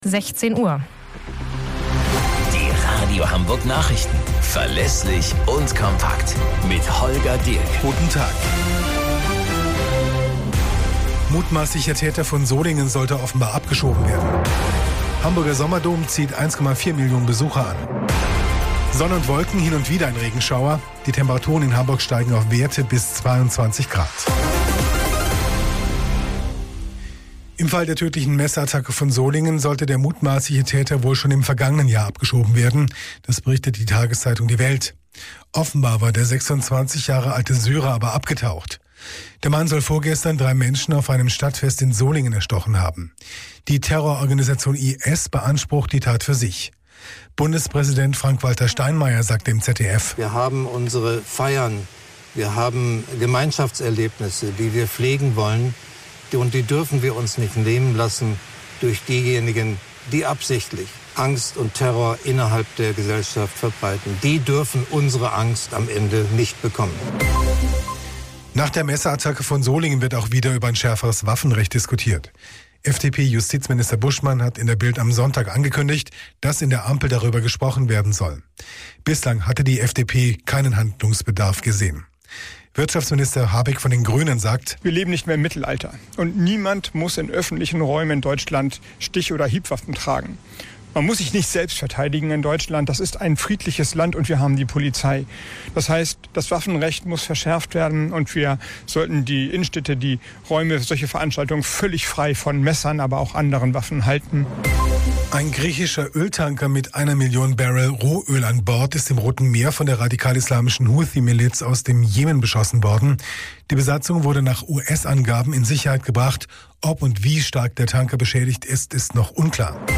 Radio Hamburg Nachrichten vom 25.08.2024 um 16 Uhr - 25.08.2024